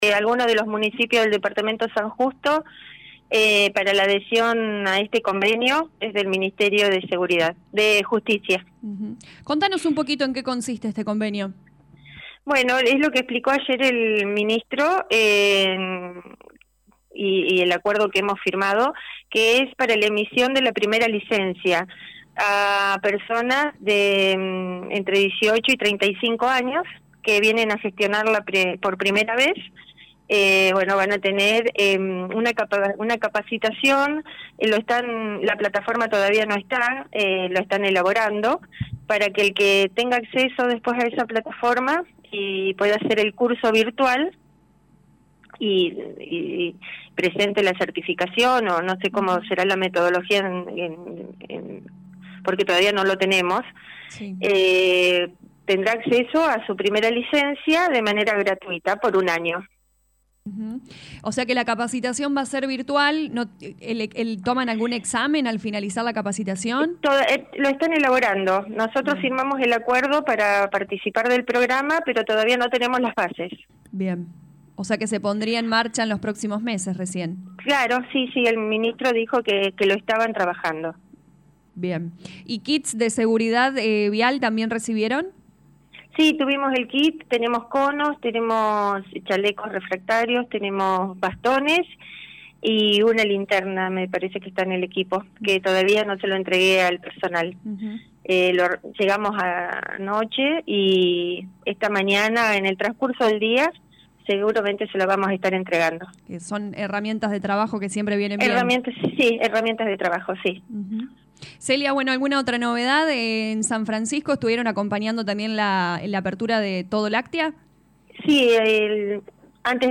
La intendente de Seeber Celia Giorgis lo confirmó en diálogo con LA RADIO 102.9 FM.